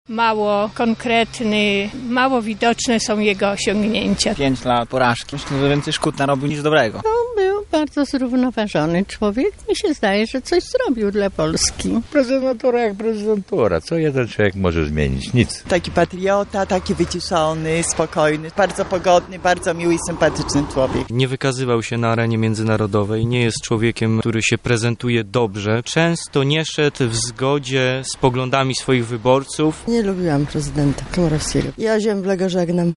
Zapytaliśmy również mieszkańców Lublina o to, jak oceniają odchodzącego prezydenta.